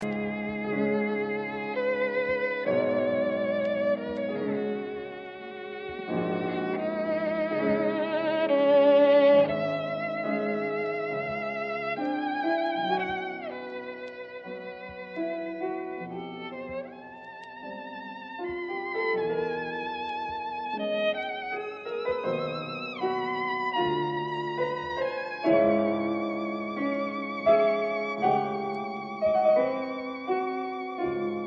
Violin
in concert and in studio